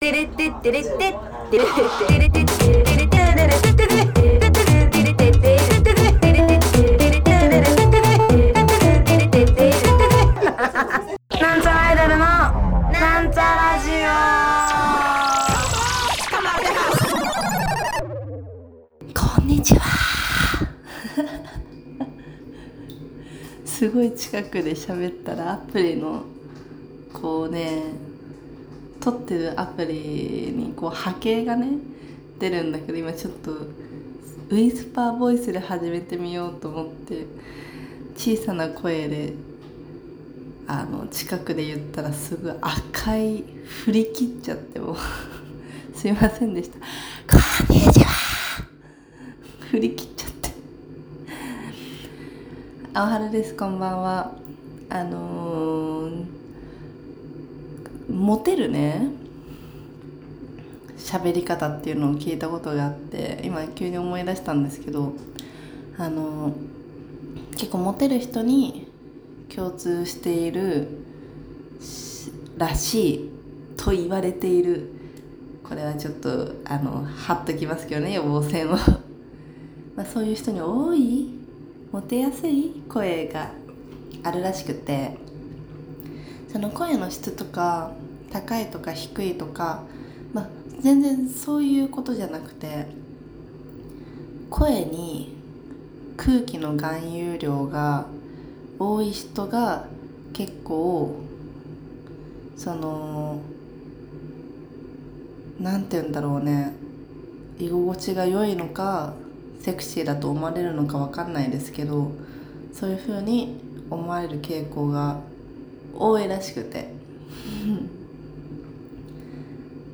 こんにちは〜！（ウィスパーボイス）（レッドゾーン）